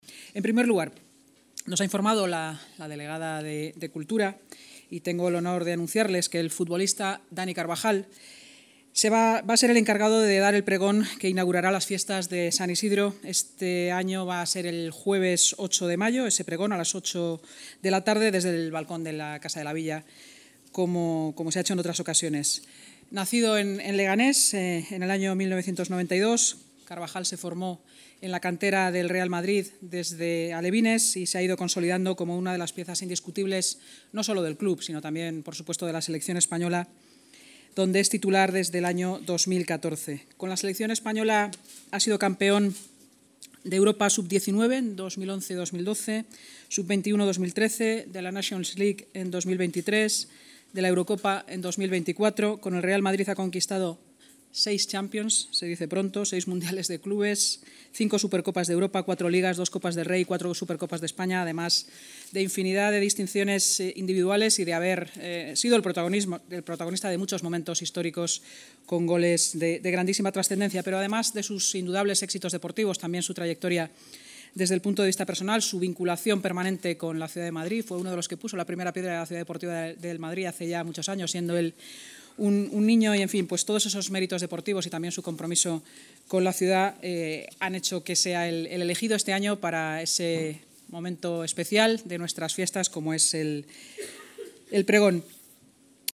Nueva ventana:La vicealcaldesa y portavoz municipal, Inma Sanz